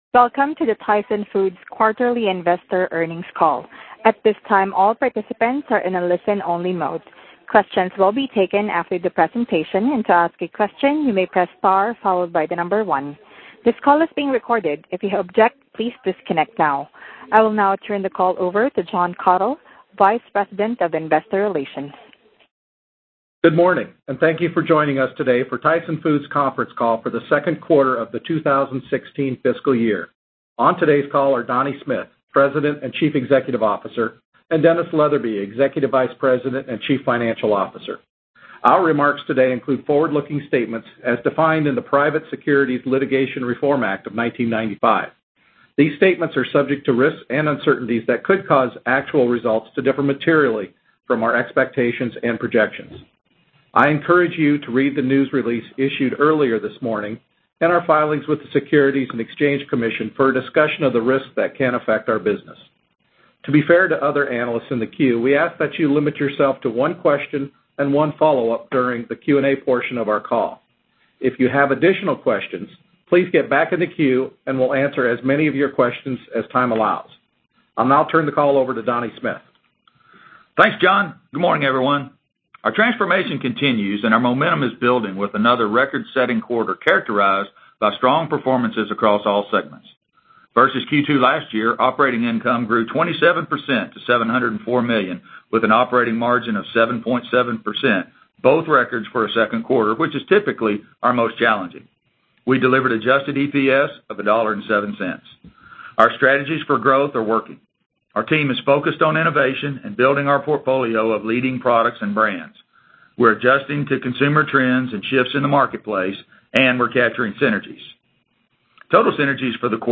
Tyson Foods Inc. - Q2 2016 Tyson Foods Earnings Conference Call